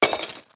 UI_BronzeFall_Soil_02.ogg